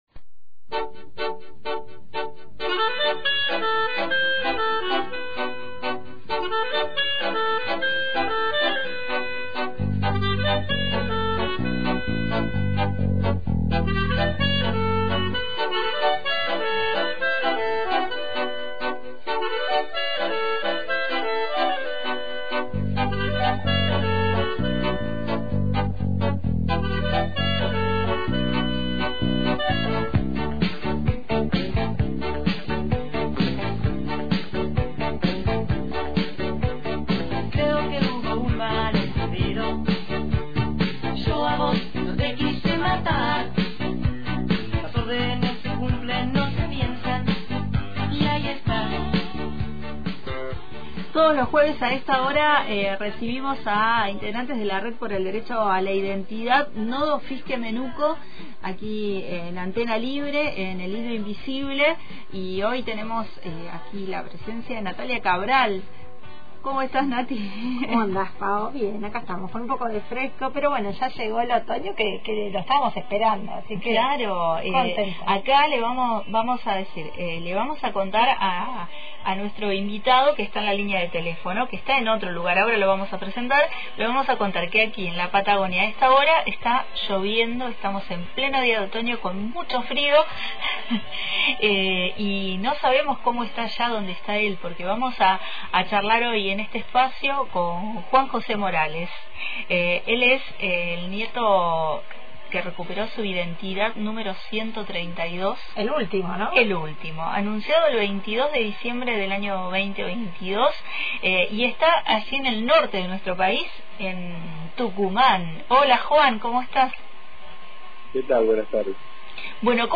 «Huellas del futuro» entrevista